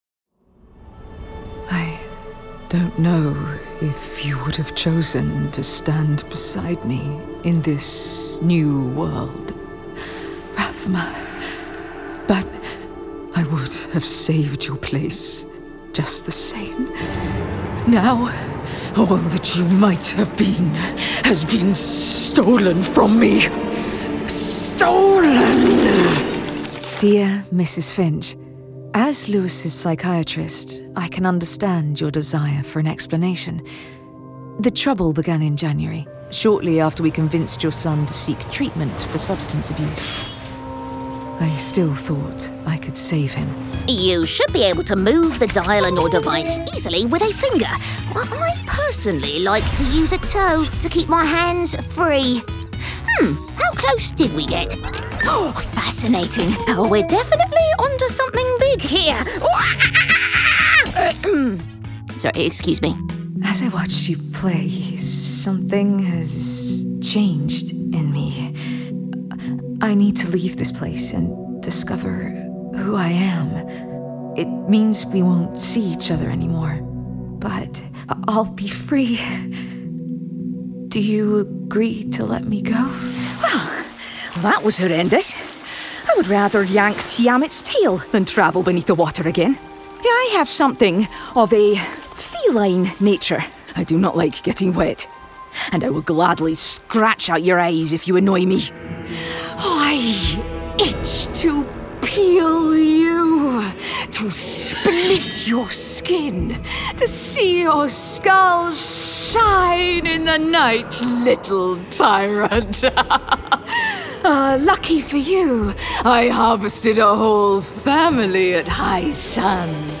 Female
English (British)
My natural vocal tone is sincere, friendly and direct with a clarity and warmth.
Video Games
Words that describe my voice are Warm, Confident, Friendly.